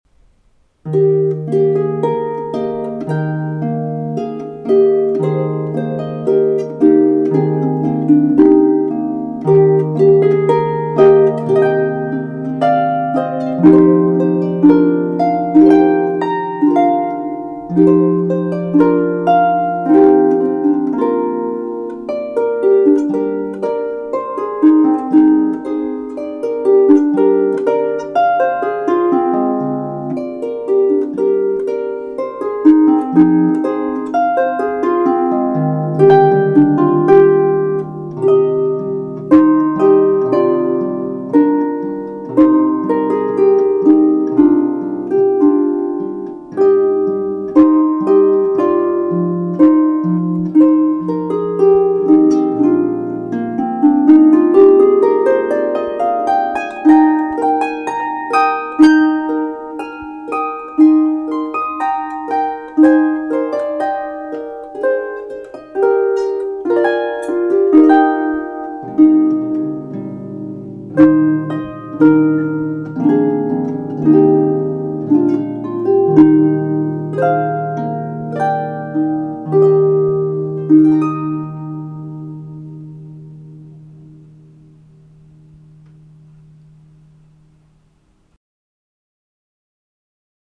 Harp music